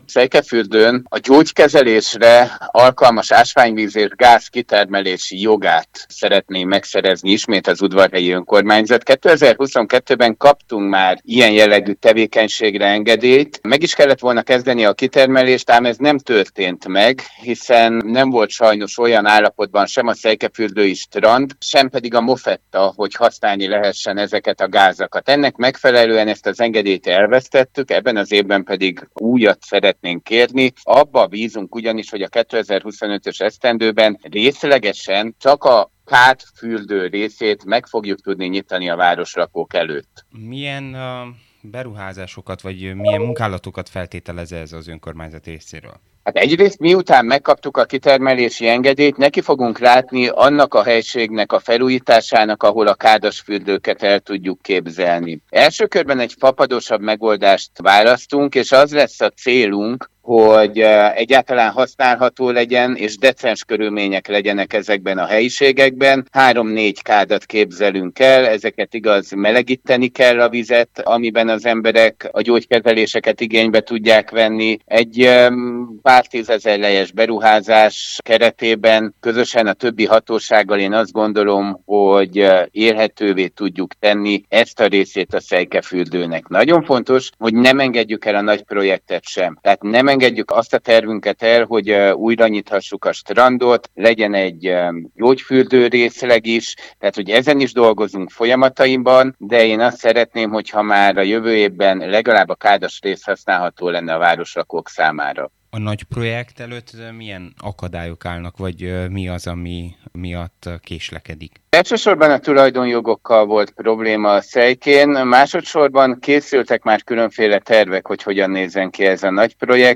A kitermelési engedély megszerzését követően a strand helyreállításának is nekifognak – mondta el a Marosvásárhelyi Rádió érdeklődésére Szakács-Paál István, Székelyudvarhely polgármestere. A városvezető elmondta: mandátuma egyik legnagyobb kihívásának tekinti a Szejkefürdő helyzetének rendezését.